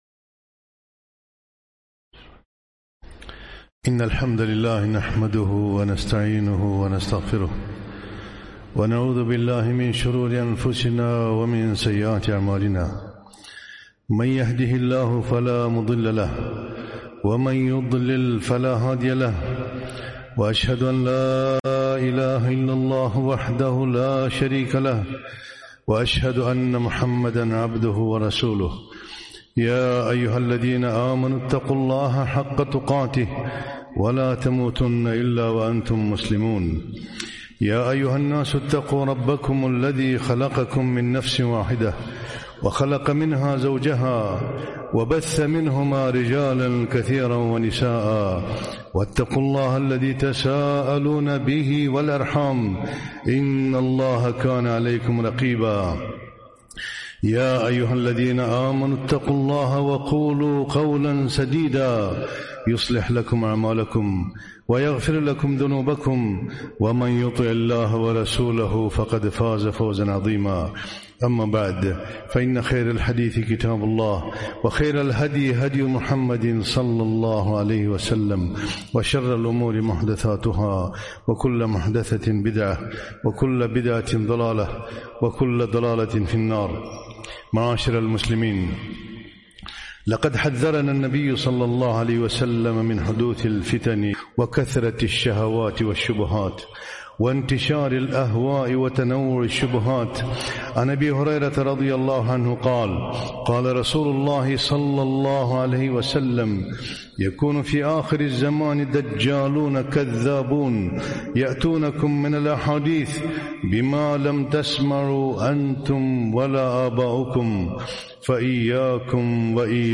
خطبة - تحذير الشباب من التطرف والإرهاب